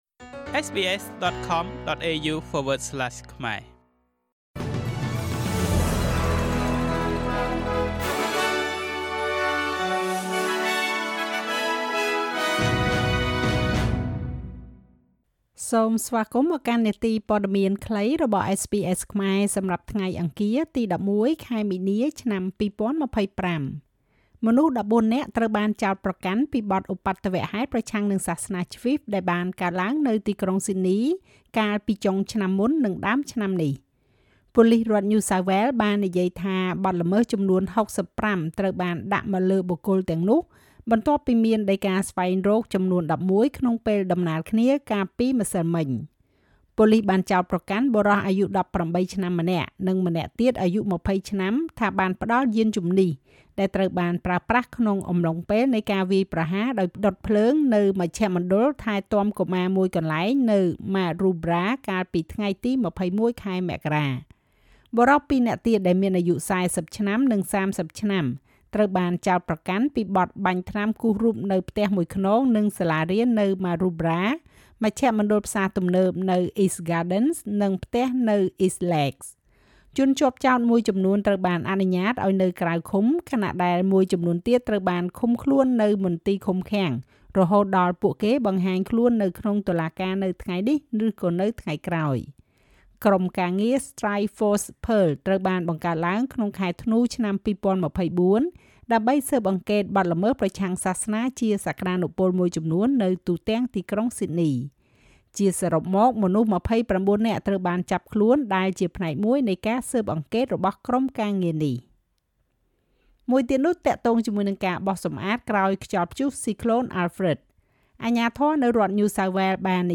នាទីព័ត៌មានខ្លីរបស់SBSខ្មែរ សម្រាប់ថ្ងៃអង្គារ ទី១១ ខែមីនា ឆ្នាំ២០២៥